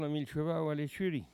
Maraîchin
locutions vernaculaires